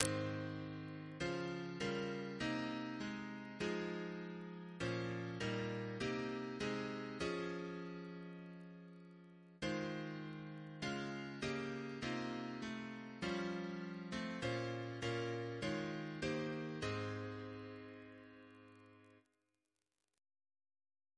CCP: Chant sampler
Double chant in A♭ Composer: Martin J. R. How (b.1931) Reference psalters: RSCM: 75